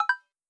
Modern UI SFX / SlidesAndTransitions